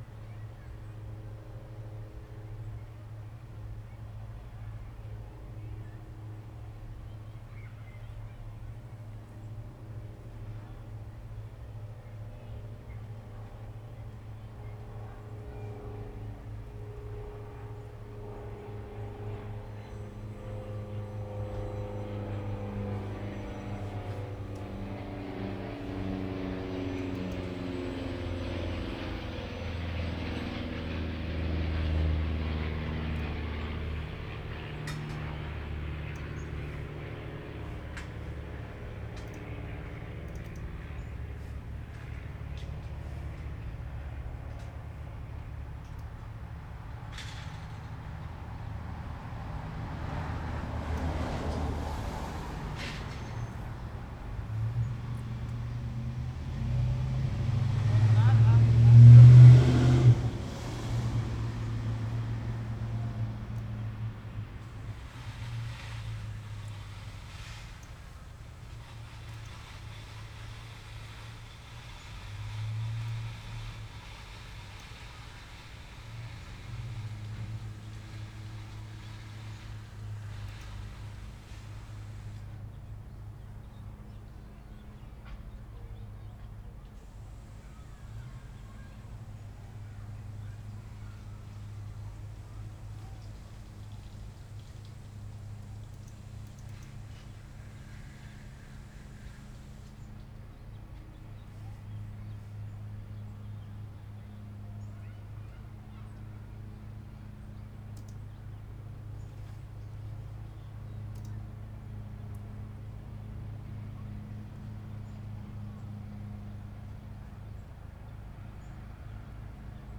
FOLDER 10 - North Burnaby (Recording by Barry Truax with an H2 Zoom Stereo Recorder)
Chevron Whistle, 5:11
7. Seaplane beginning at 0:20, car at 0:50, 1:00, children playing at a distance audible at 2:08, motors as keynote - the din of the harbour, nearby roads & lawn mower, birds at 2:42, Chevron whistle beginning at 3:55 ending at 4:15.